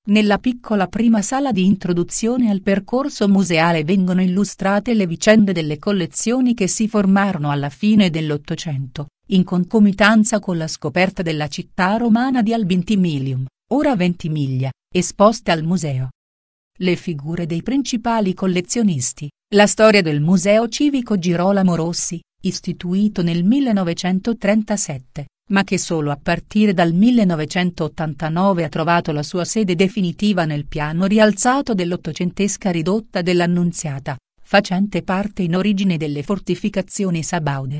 ITA Audioguida Sala 1